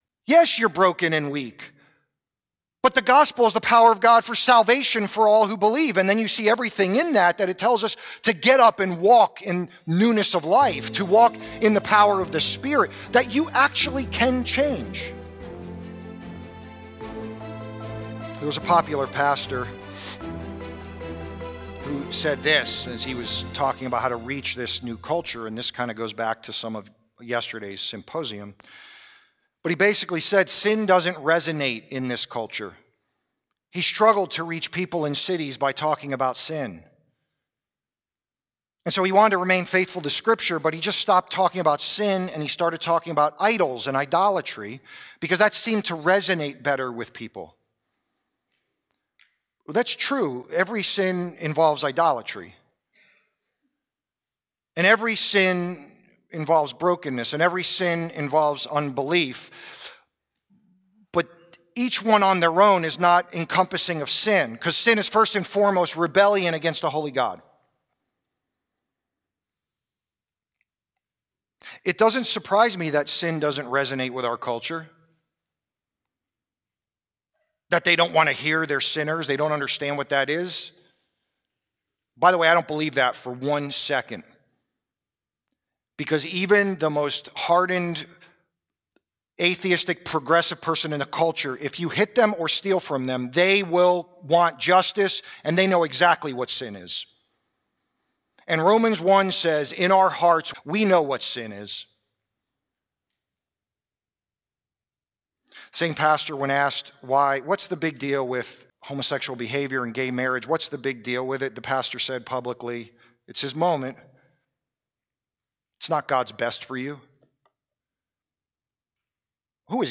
sermon clips